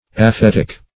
Search Result for " aphetic" : Wordnet 3.0 ADJECTIVE (1) 1. produced by aphesis ; The Collaborative International Dictionary of English v.0.48: Aphetic \A*phet"ic\, a. [Gr.